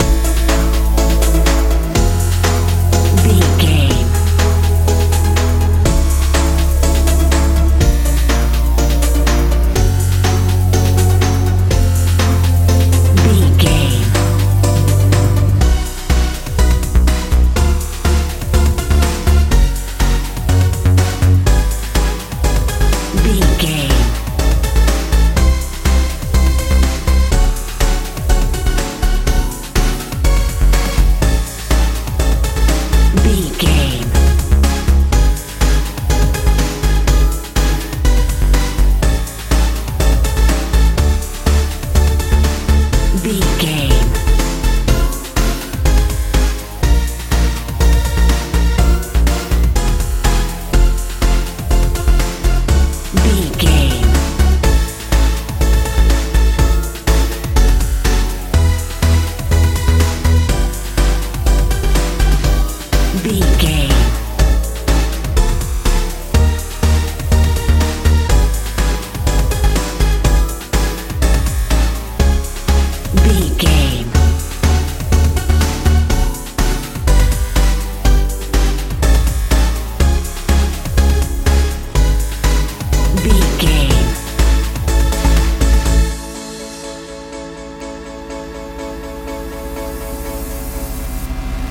dance pop feel
Ionian/Major
futuristic
powerful
synthesiser
bass guitar
drums
strange
heavy